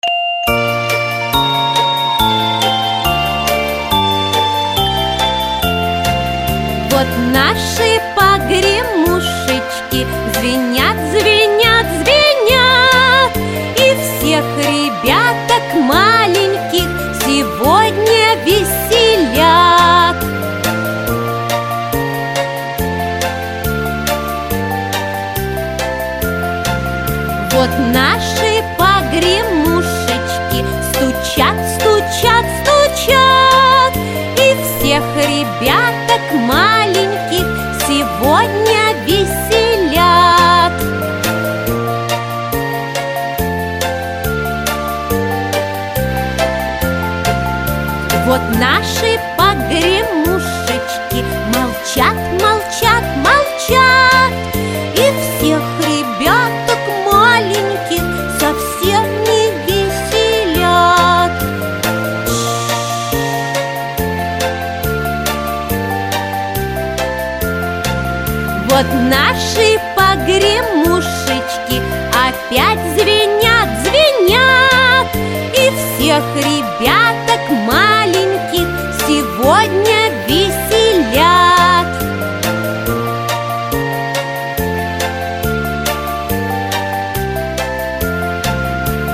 • Качество: Хорошее
• Категория: Детские песни
На мотив песни "В лесу родилась ёлочка"